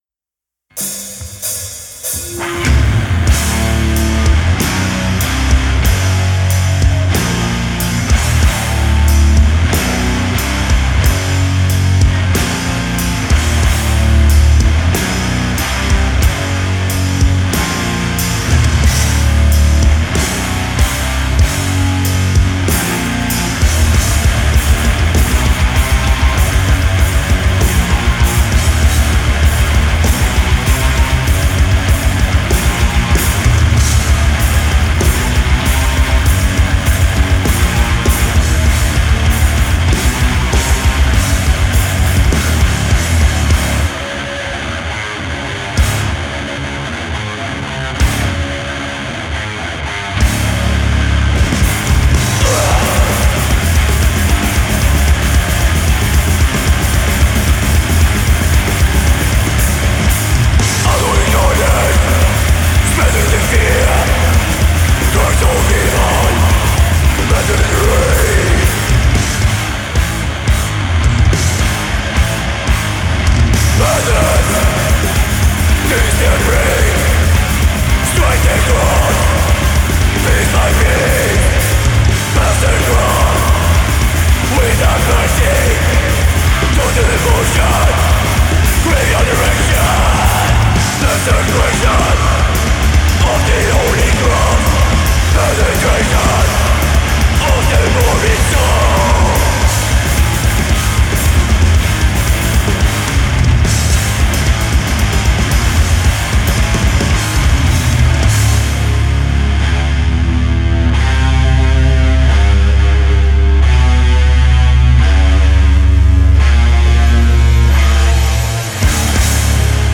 Genre: Metal
This is Swedish death 101.